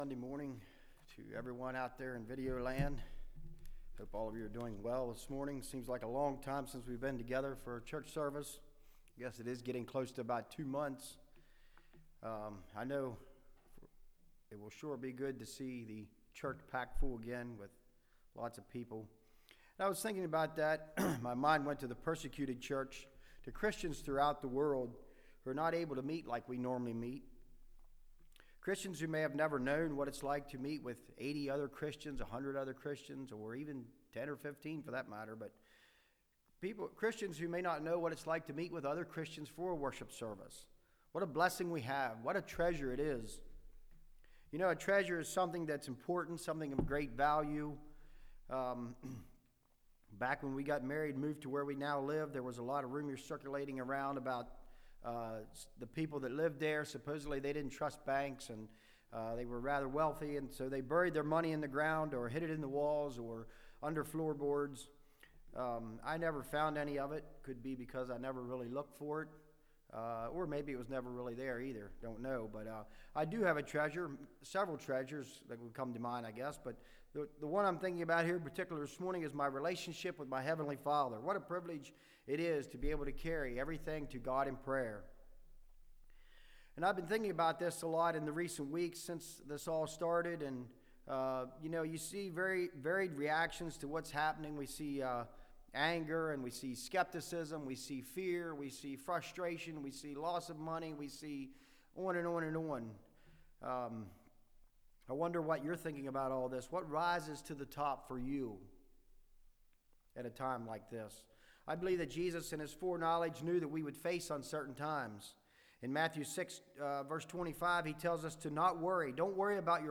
1 Timothy 6 Service Type: Message Bible Text